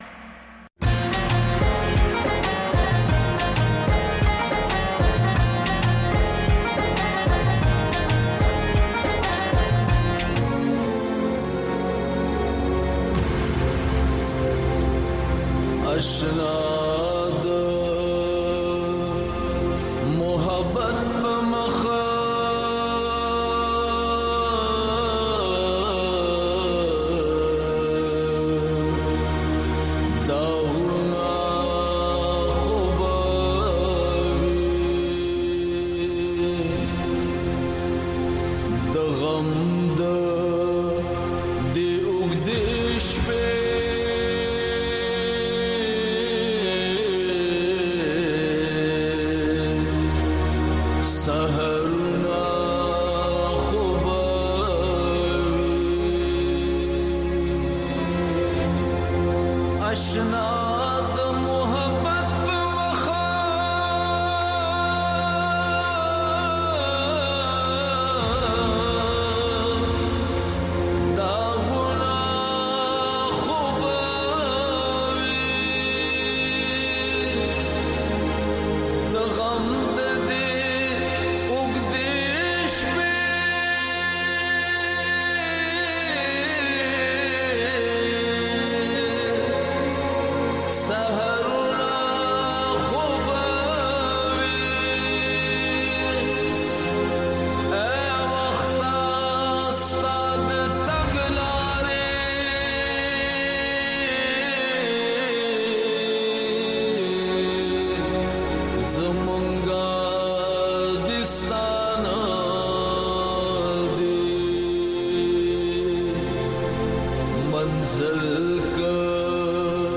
دې یو ساعته پروگرام کې تاسو خبرونه او د هغې وروسته، په یو شمېر نړیوالو او سیمه ایزو موضوگانو د میلمنو نه پوښتنې کولی شۍ.